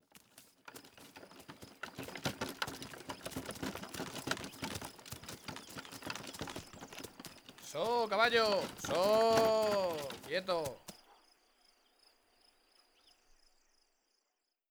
Pasada de un carreta con arriero diciendo: Soooo
arriero
caballo
Sonidos: Rural